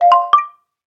notification_009.ogg